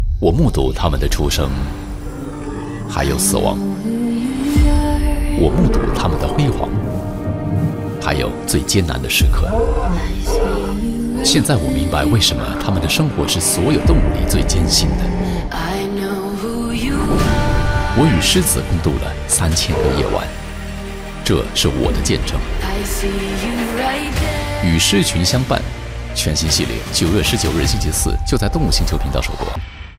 Voice Samples: Animal Planet Documentary Read
male